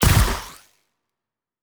pgs/Assets/Audio/Sci-Fi Sounds/Weapons/Sci Fi Explosion 22.wav at master
Sci Fi Explosion 22.wav